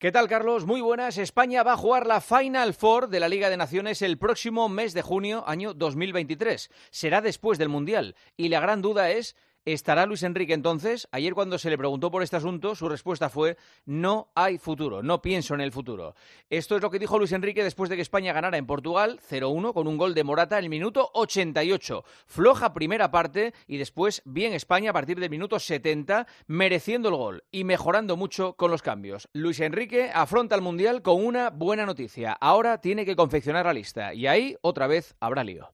El comentario de Juanma Castaño
El director de 'El Partidazo de COPE' analiza la actualidad deportiva en 'Herrera en COPE'